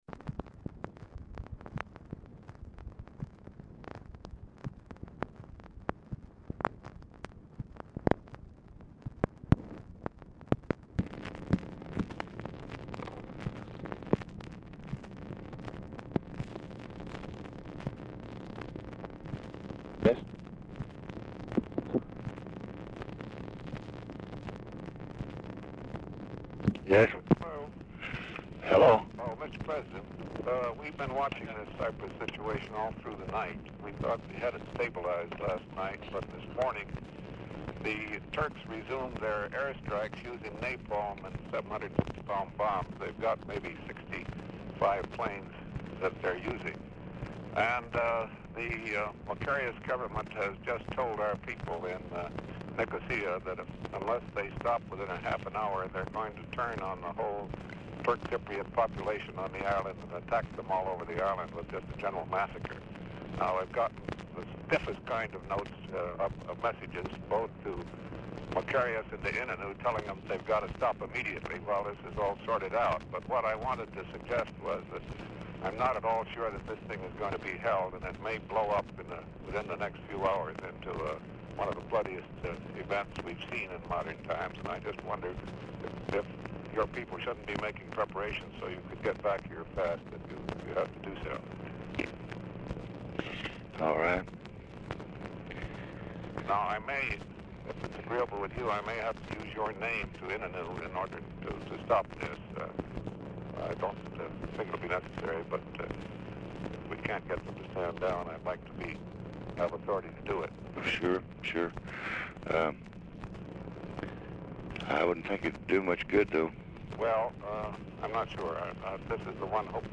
Title Telephone conversation # 4829, sound recording, LBJ and GEORGE BALL, 8/9/1964, 6:50AM Archivist General Note "RE CYPRUS SITUATION"; SLIP LISTS CALL AT 6:50A, DAILY DIARY AT 6:56A.
Format Dictation belt
Location Of Speaker 1 LBJ Ranch, near Stonewall, Texas